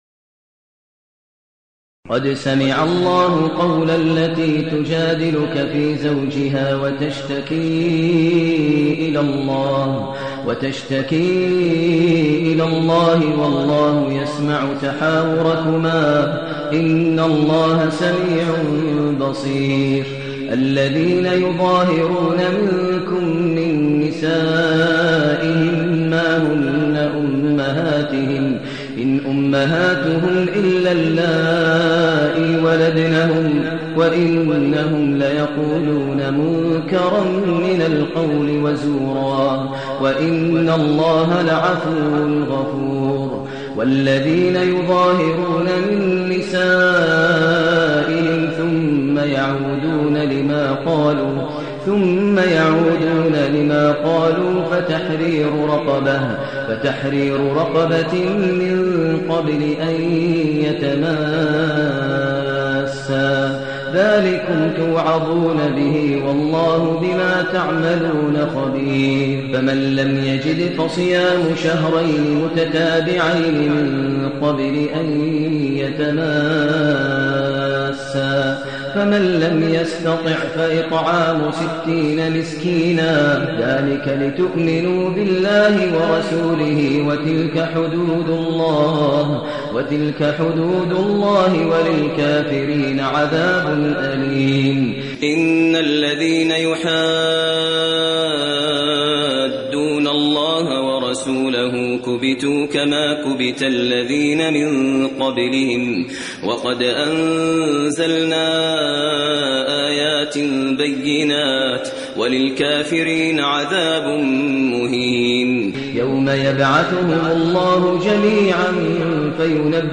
المكان: المسجد الحرام الشيخ: فضيلة الشيخ ماهر المعيقلي فضيلة الشيخ ماهر المعيقلي المجادلة